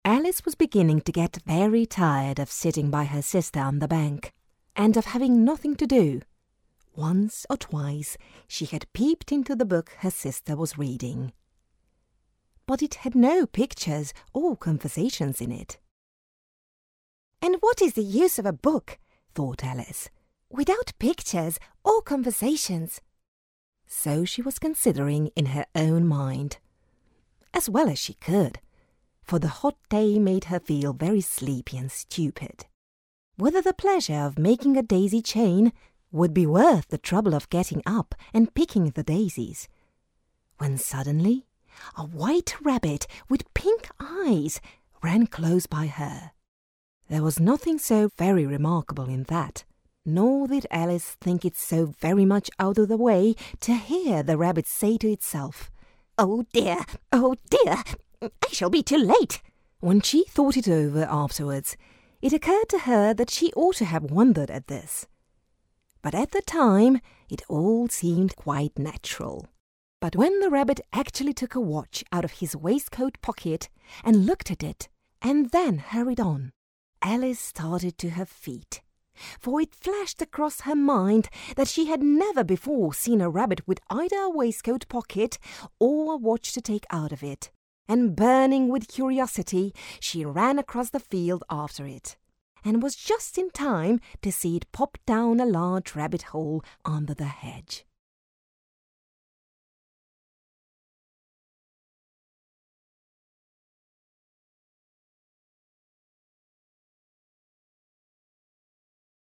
Voice-Over
Narration
Narration.mp3